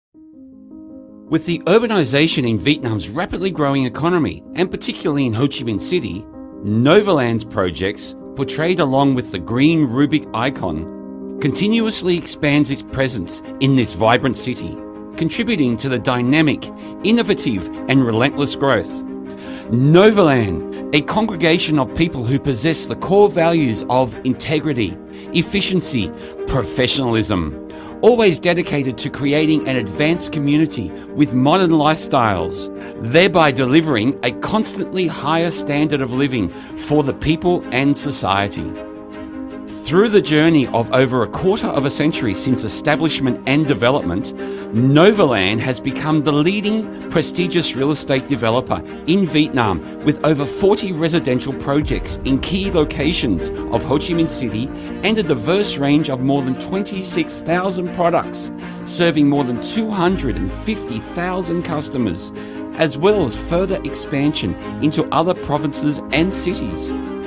Giọng nam người Úc